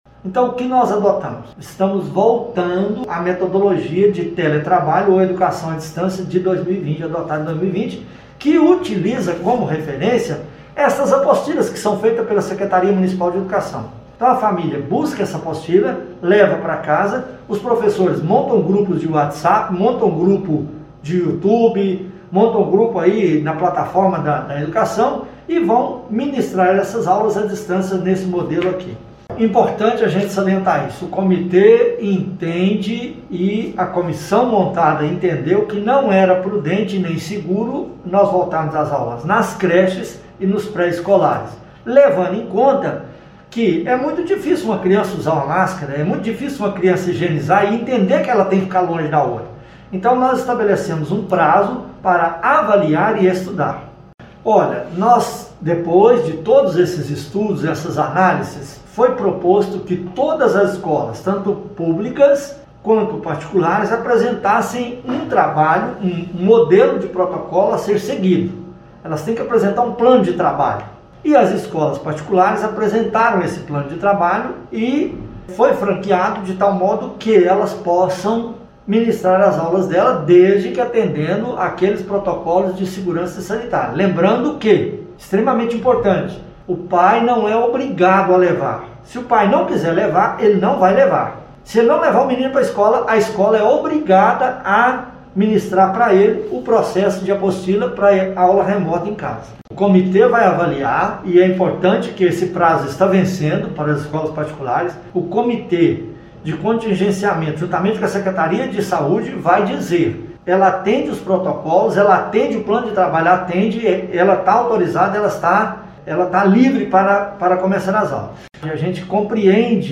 A reportagem da Rádio Veredas ouviu o Secretário de Educação, professor Geraldo Magela, que fez um balanço desde as discussões iniciais para a volta das atividades escolares pelo método presencial. O Gestor da Pasta da Educação informou que as aulas na Rede Municipal de Educação, reiniciam na data programada, porém, no modelo de Ensino a Distância, a exemplo do que ocorreu no ano passado.